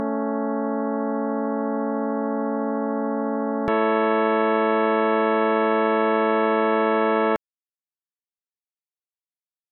A-Dur-Kadenz
Anhören pythagoreisch/?
Die "geschärfte" Terz in gleichstufiger (400 Cent) oder gar pythagoreischer (408 Cent) Stimmung mit einer starken Schwebung wird als Reibung empfunden.
A_cis_pythagoreisch.ogg.mp3